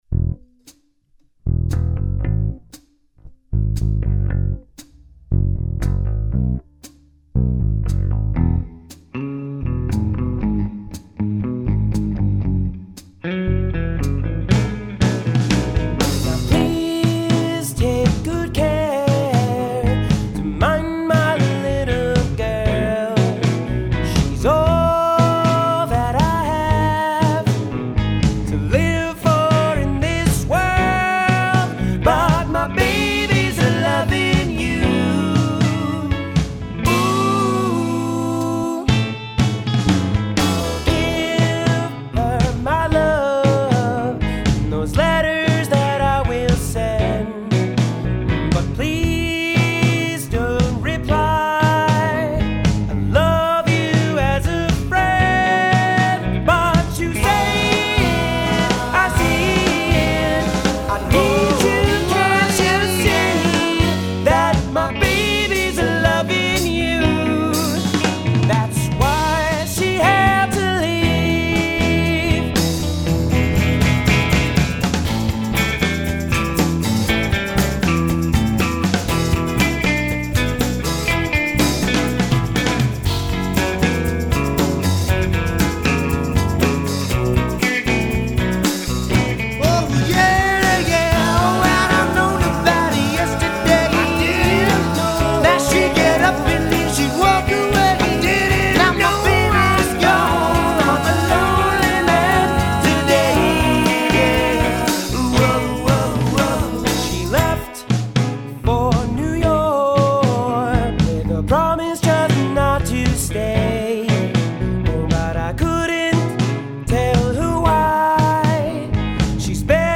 Teen band battle